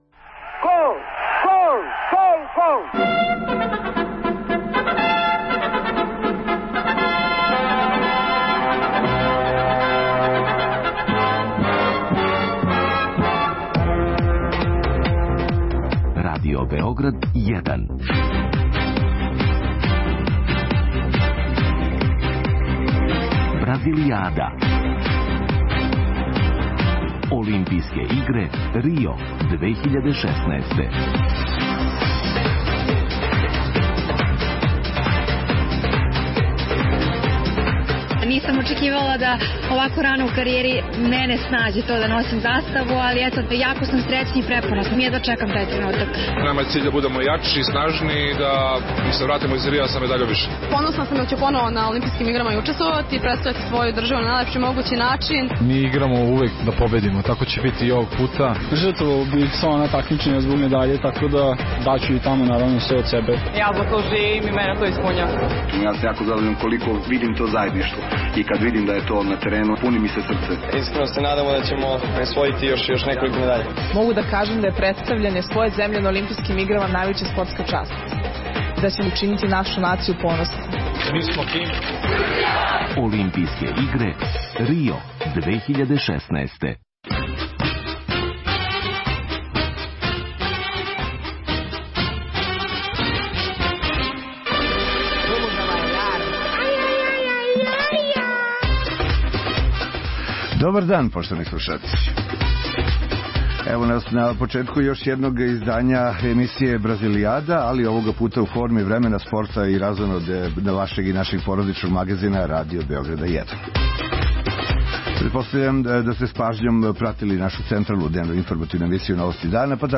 Велики број спортиста Србије данас почиње такмичење на 31. Олимпијским играма у Рију, па ће већи део емисије протећи управо у извештајима из Бразила. Као и обично, чућемо вести из прве руке, с лица места, коментаре, анализе, изјаве наших такмичара.
Нећемо, наравно, заборавити ни дешавања ван олимпијског села, најављујемо велики број манифестација који се ових дана одржавају у Србији, као и вести из света уметности, уз занимљиве госте у студију.